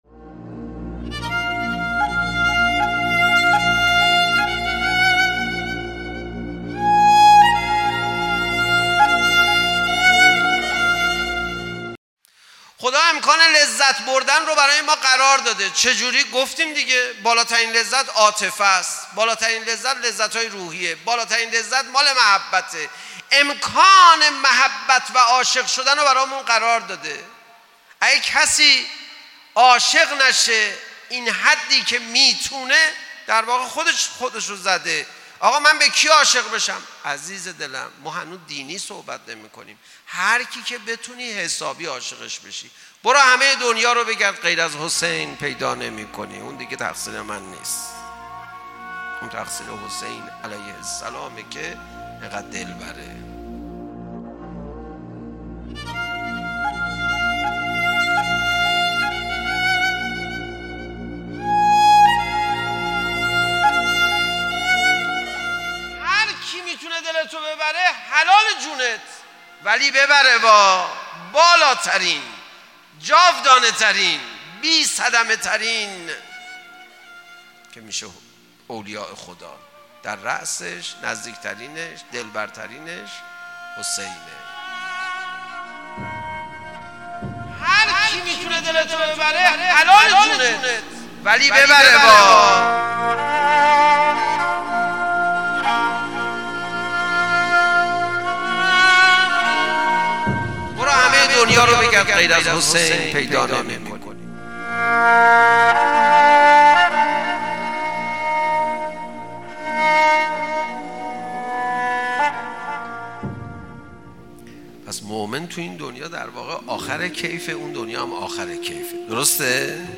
دانلود کلیپ تصویری سخنرانی استاد علیرضا پناهیان در مورد امام حسین علیه السلام با عنوان «دلبرترین معشوق عالم»